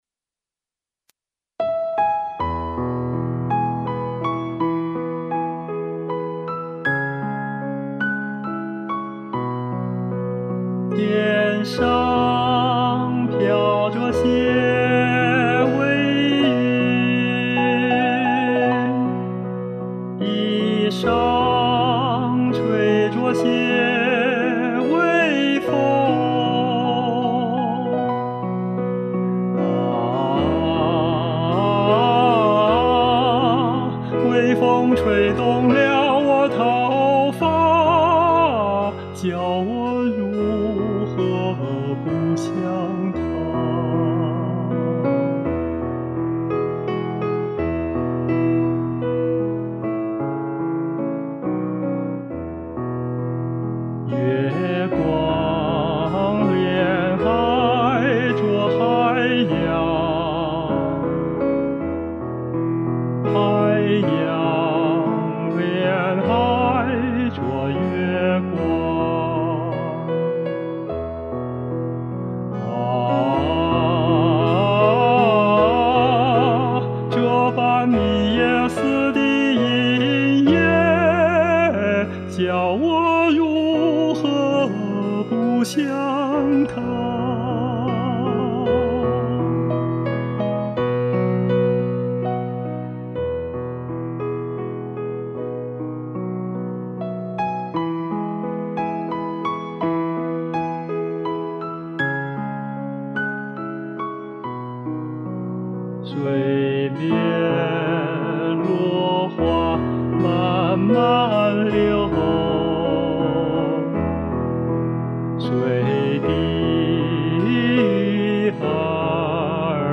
砸得具體的，比如歌曲情景理解和描述，歌譜中的音符長短強弱，修飾音等等，都在實話版中盡力去改進了。